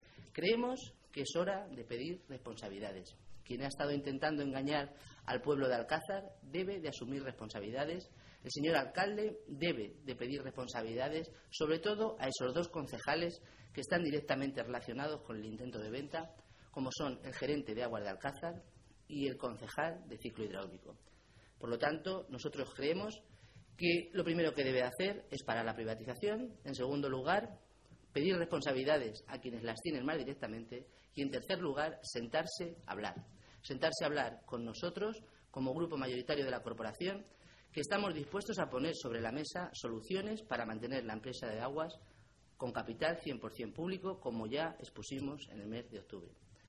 Rosa Melchor, diputada regional del Grupo Socialista
Cortes de audio de la rueda de prensa